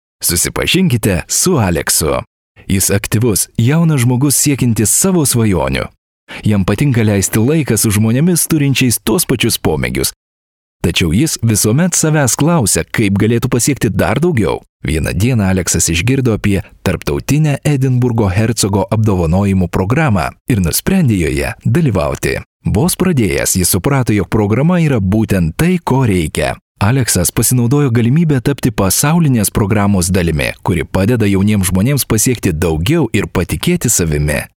Lithuanian voice over artist native
Sprechprobe: Industrie (Muttersprache):